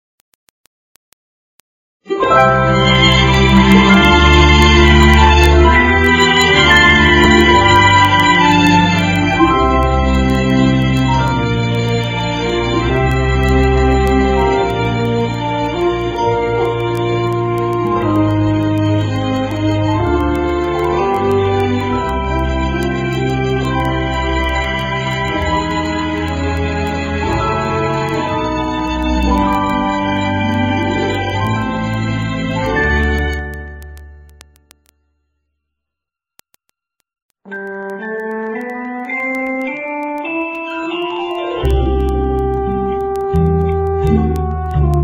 NOTE: Background Tracks 11 Thru 22